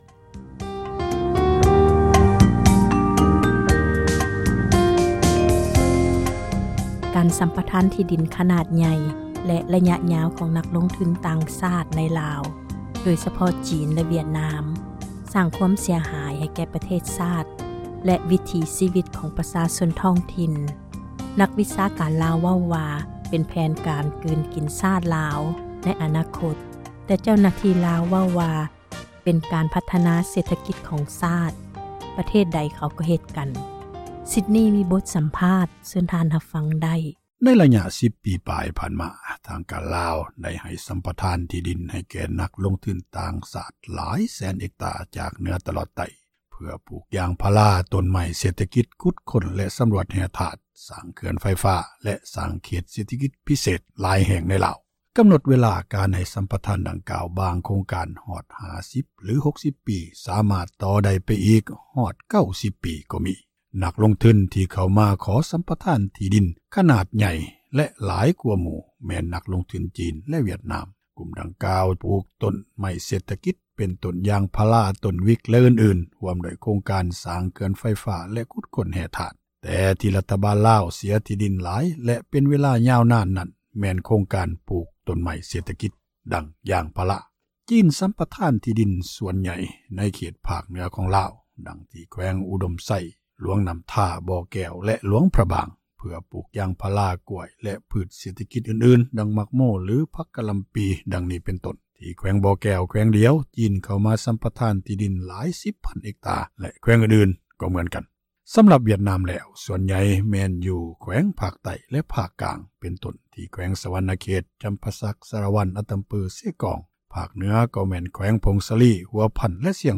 ມີບົດ ສຳພາດ.